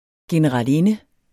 generalinde substantiv, fælleskøn Bøjning -n, -r, -rne Udtale [ genəʁɑlˈenə ] Betydninger 1.